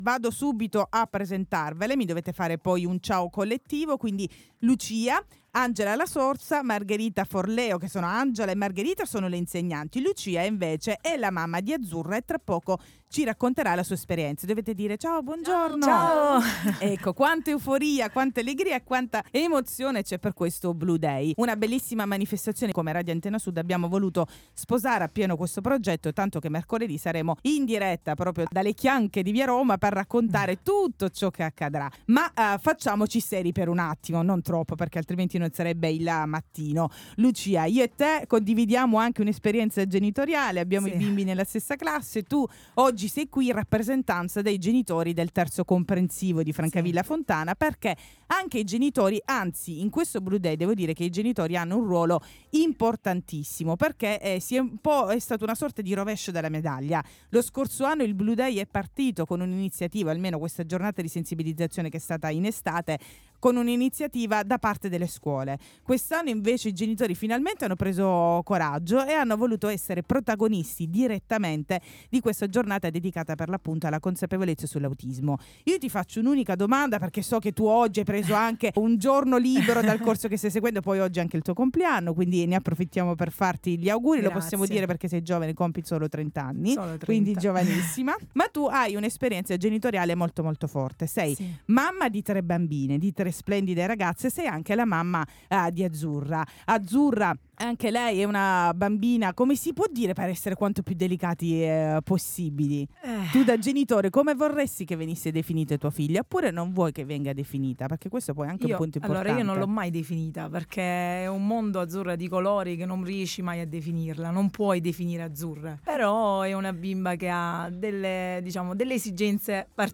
IL MATTINO per il BLU DAY con i genitori e le insegnanti del Terzo Comprensivo di Francavilla Fontana